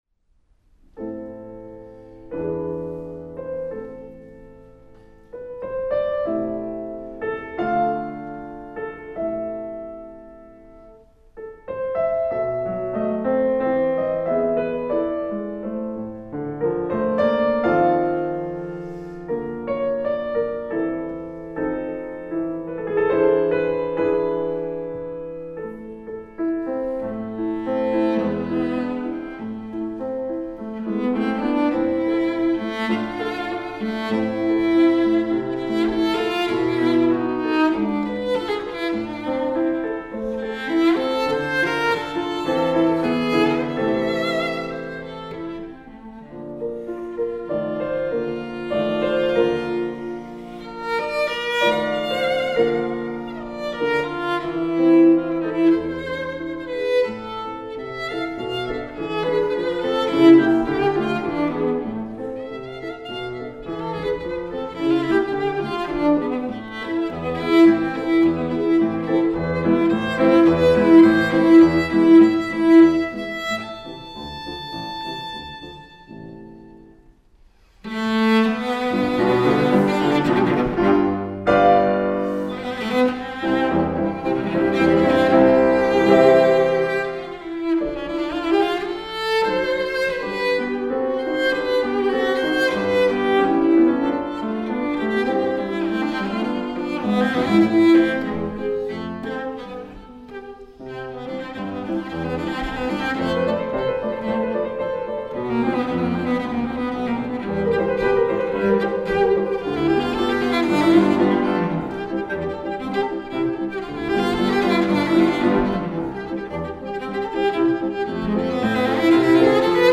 The piano opens the first movement, followed by the cello ten bars later; they present a dark, unusually meditative theme for the first subject of a Sonata. However it does contain the seeds of a second, highly-cheeky theme which quickly follows.
Venue: St. Brendan’s Church
Instrumentation: vc, pf Instrumentation Category:Duo
Thomas Larcher - [piano] Natalie Clein - [cello]